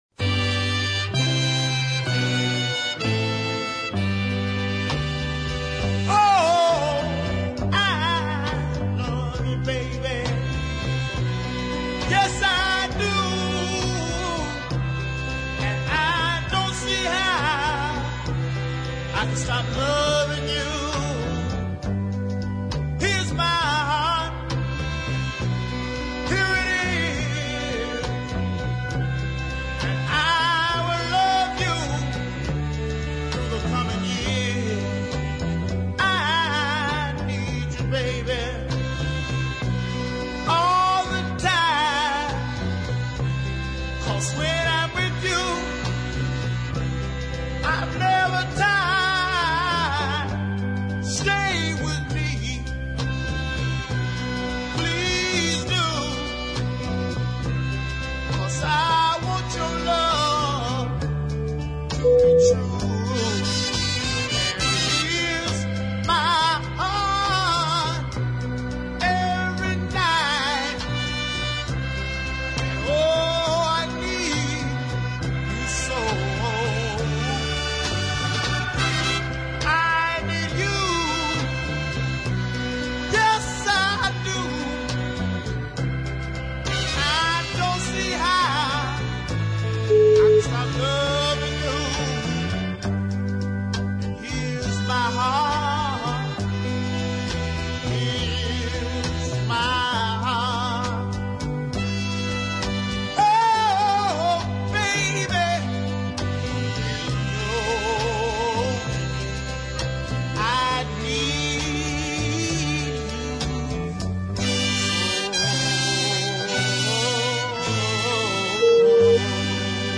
is taken at a brisker pace